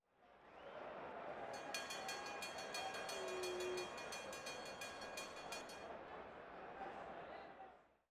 Última vuelta en una carrera de atletismo
Sonidos: Gente
Sonidos: Acciones humanas
Sonidos: Deportes